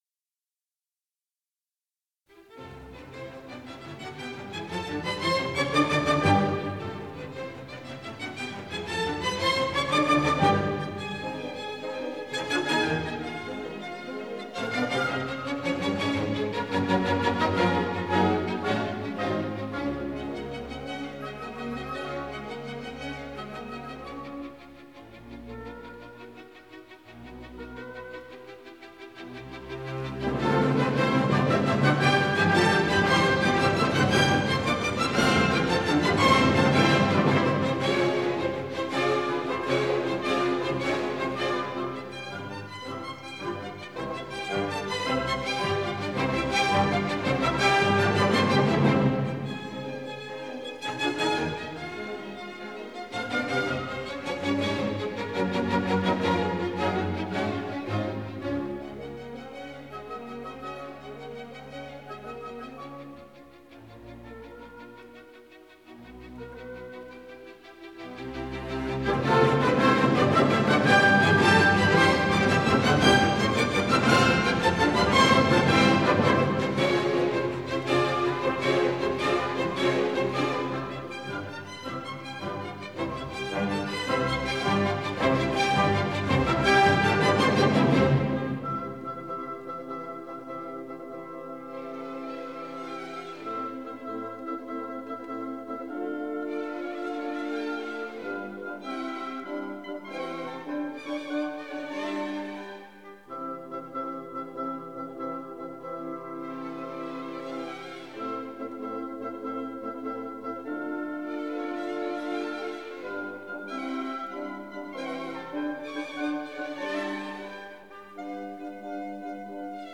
1961 stereo recording